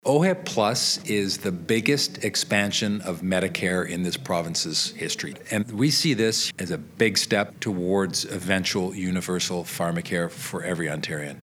Recording Location: toronto
Type: News Reports